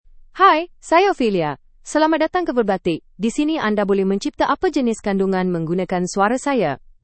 OpheliaFemale Malayalam AI voice
Ophelia is a female AI voice for Malayalam (India).
Voice sample
Listen to Ophelia's female Malayalam voice.
Ophelia delivers clear pronunciation with authentic India Malayalam intonation, making your content sound professionally produced.